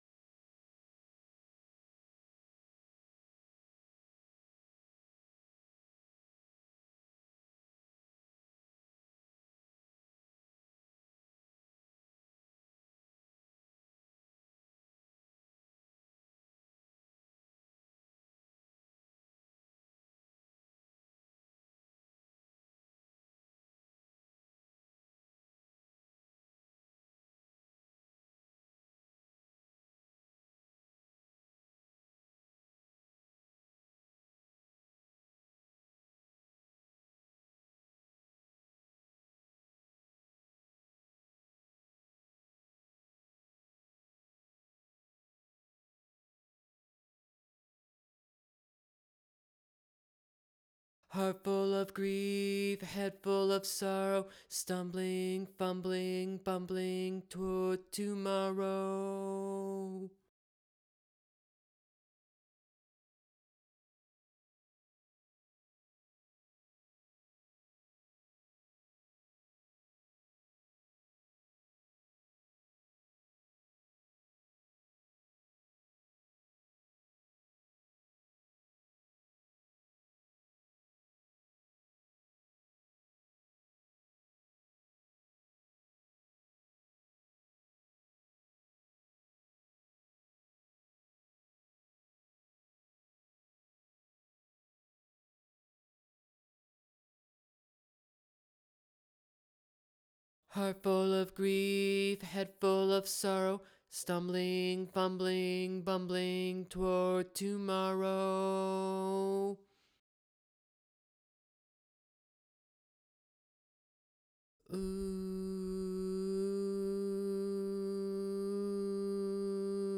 Toward Tomorrow - Vocal Chorus - Mid High.wav